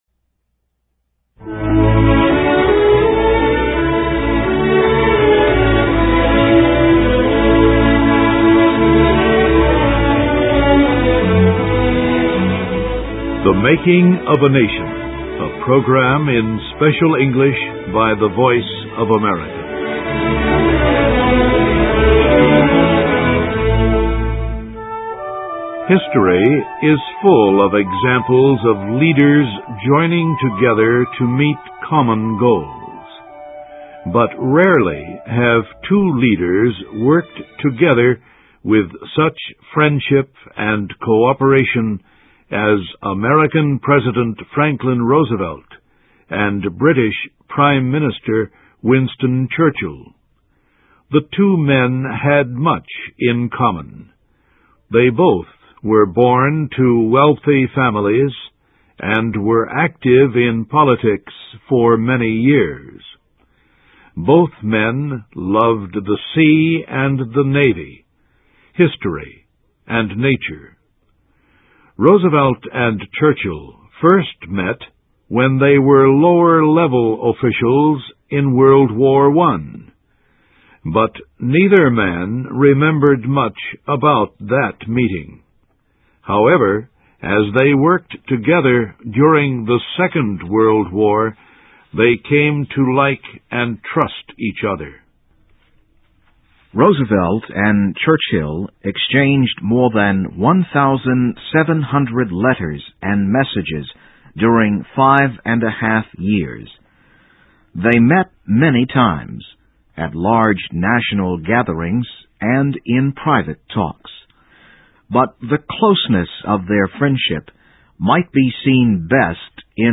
THE MAKING OF A NATION �C a program in Special English by the Voice of America.